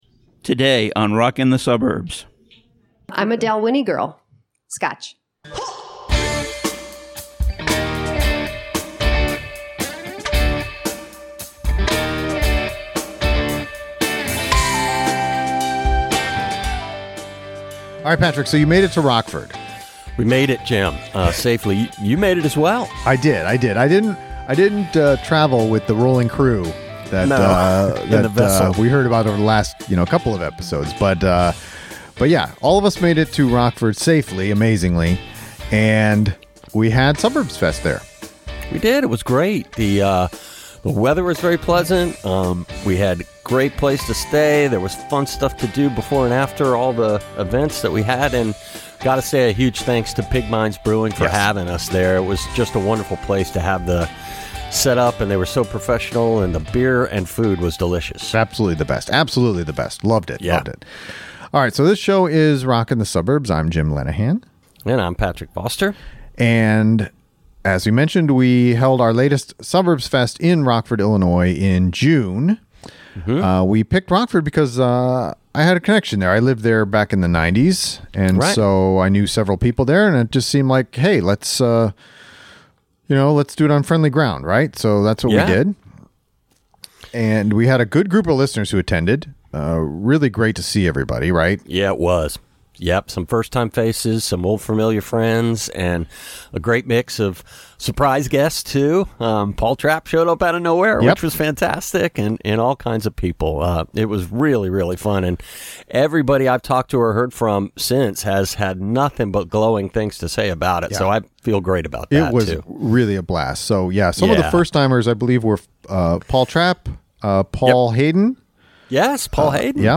Live songs: 1.